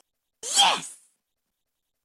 YES!!! sound effect
Thể loại: Âm thanh meme Việt Nam
Description: YES!!! sound effect giọng con nút, trẻ con, giọng nữ... chính là lựa chọn hoàn hảo. Đây là một hiệu ứng âm thanh dạng tiếng nói meme, thường được dùng trong những khoảnh khắc vui nhộn, chiến thắng hay gây cười thường thấy trong CapCut.
yes-sound-effect-www_tiengdong_com.mp3